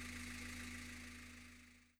carEngineEnd.wav